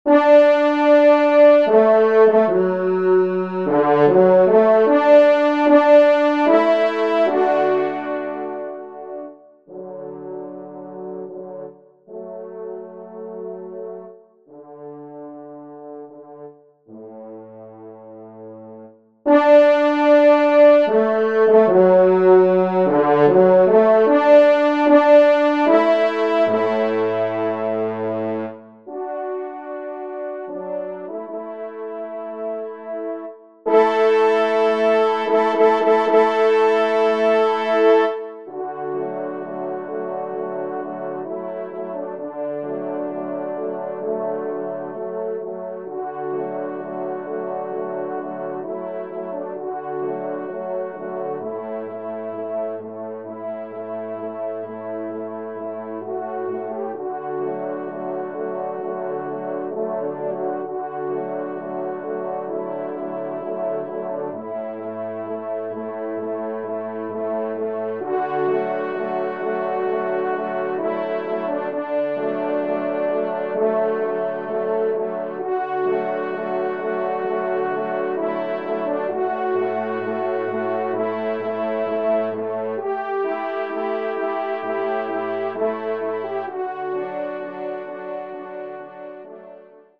Genre :  Divertissement pour Trompes ou Cors en Ré
ENSEMBLE